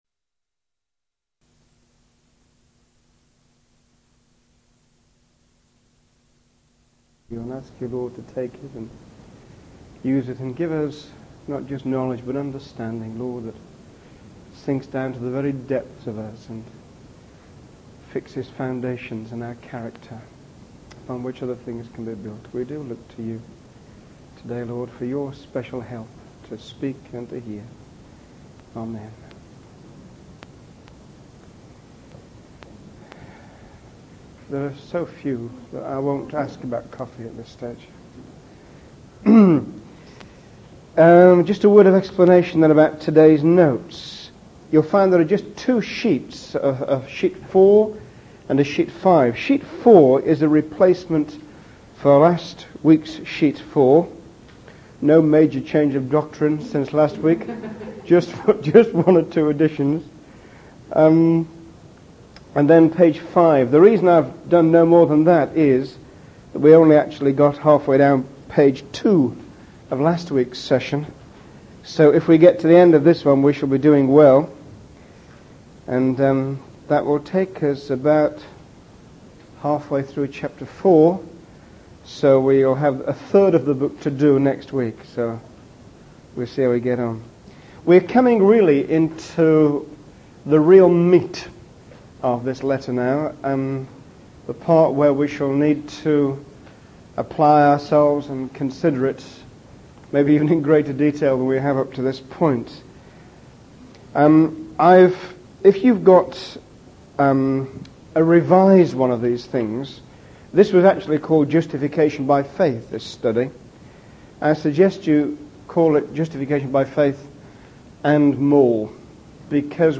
In this sermon, the speaker begins by acknowledging the importance of understanding the word of God and its impact on our lives. The sermon focuses on the concept of justification by faith, which is the foundation of our relationship with God. The speaker emphasizes that justification by faith is not the end of the story, but rather the beginning, as it leads us into a deeper understanding of God's plan for us.